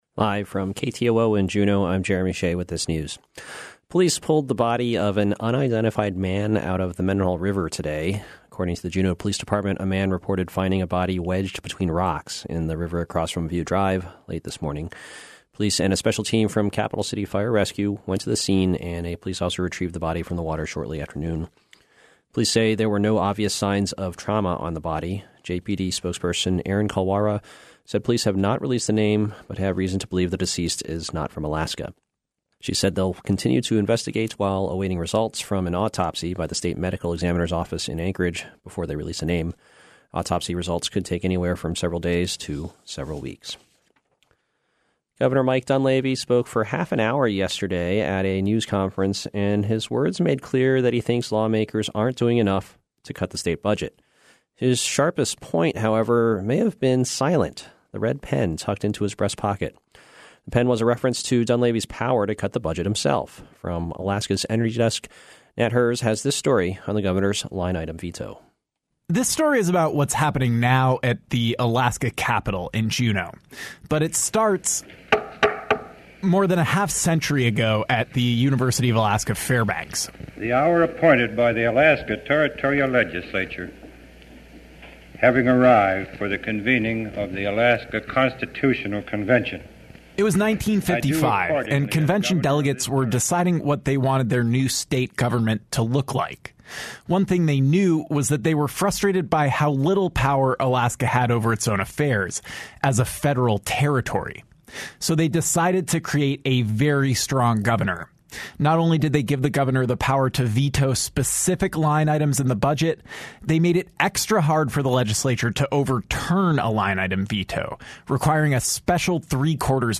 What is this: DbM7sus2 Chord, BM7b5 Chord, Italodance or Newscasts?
Newscasts